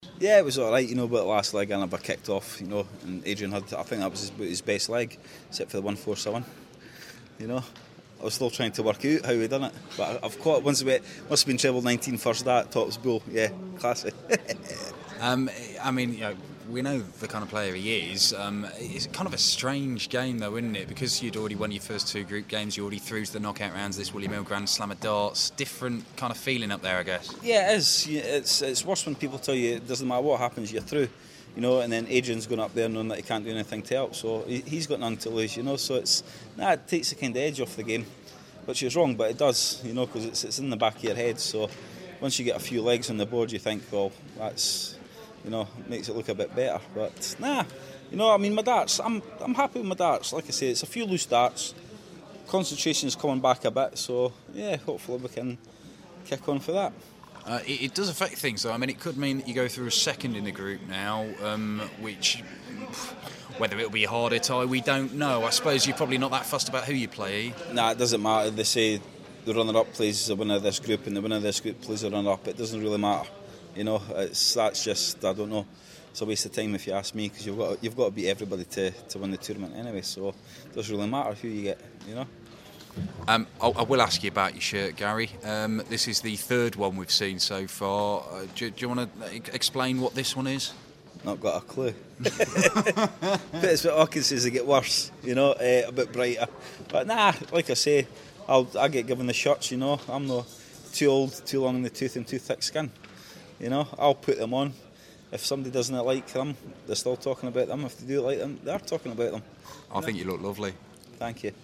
William Hill GSOD - Anderson Interview (3rd game)